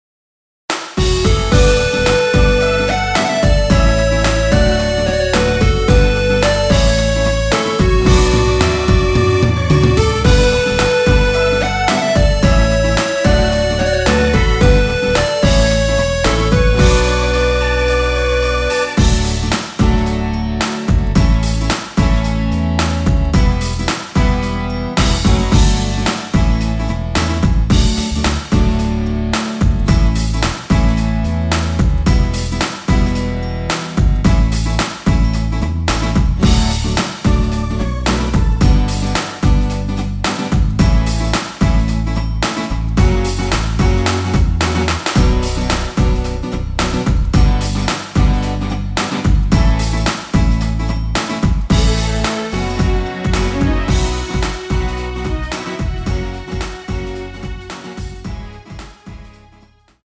원키에서(-2)내린 MR입니다.
앞부분30초, 뒷부분30초씩 편집해서 올려 드리고 있습니다.